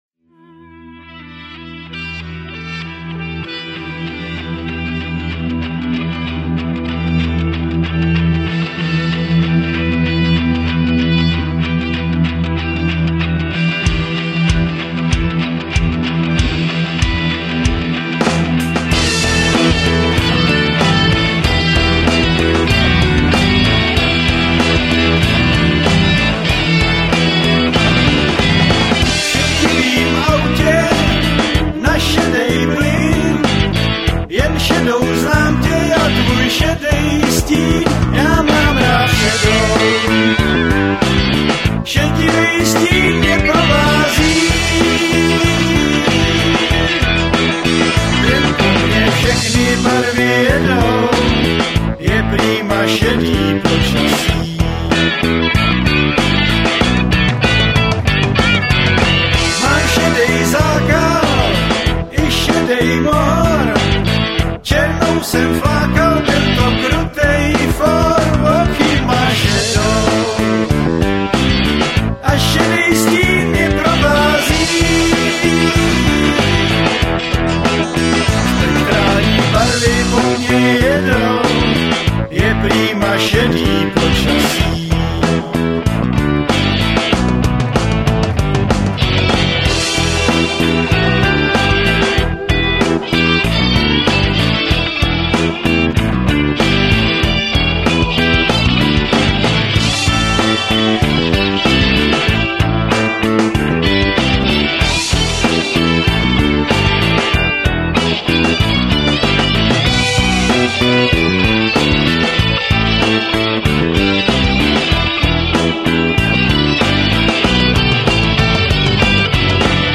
bicí a percussions
baskytara
flétna, alt-saxofon, harmonika, zpěv
kytary, zpěv